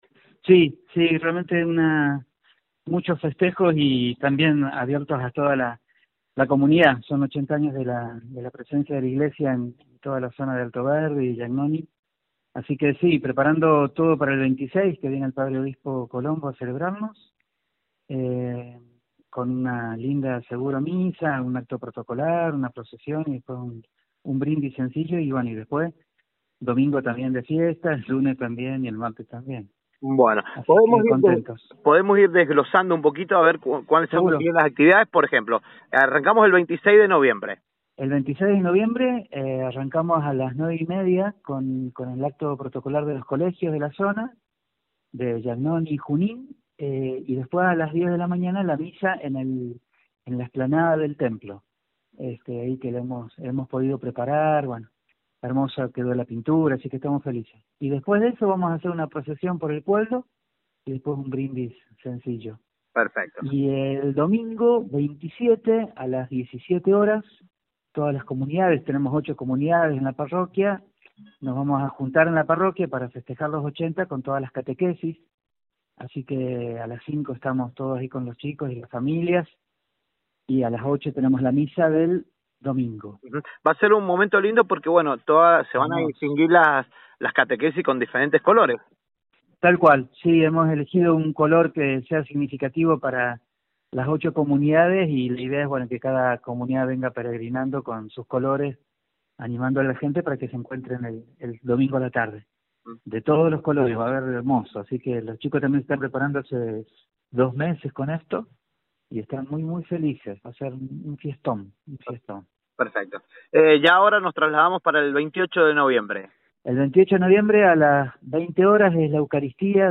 A continuación, la entrevista completa